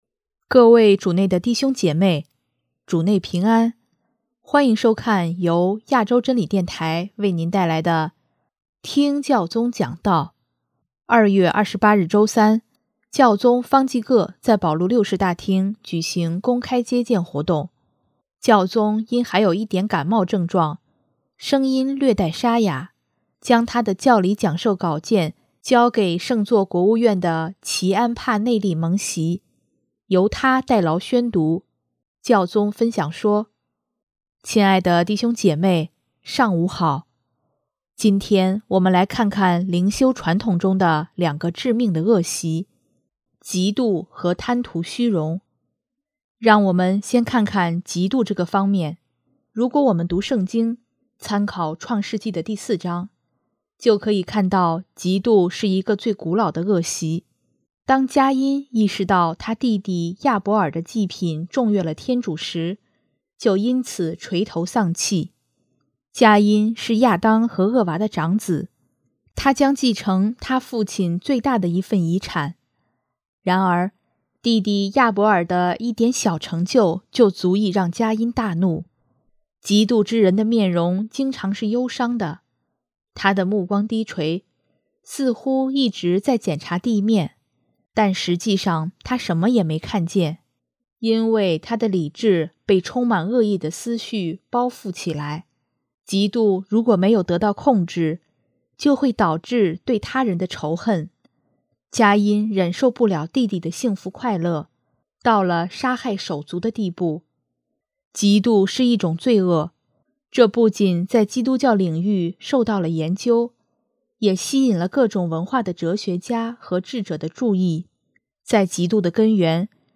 【听教宗讲道】|灵修中的两个致命的恶习：嫉妒和贪图虚荣